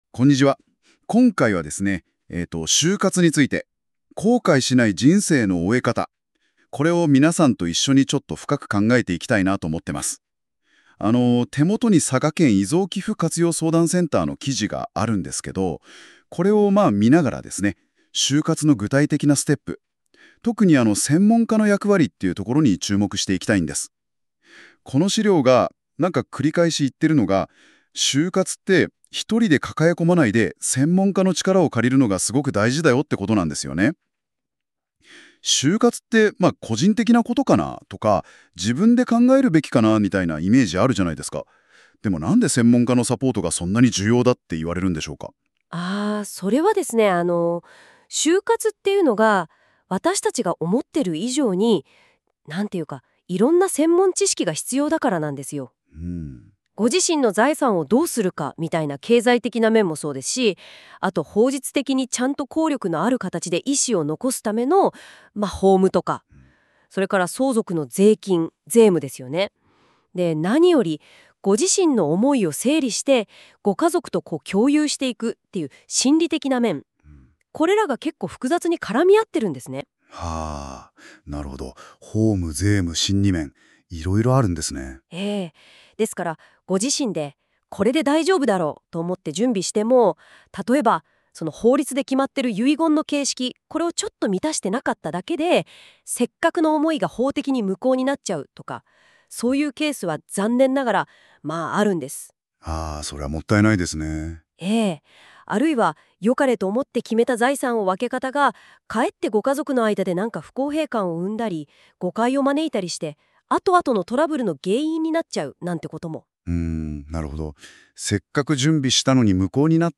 このページの内容をAI要約音声で聞きたい場合は、下の再生ボタンをクリック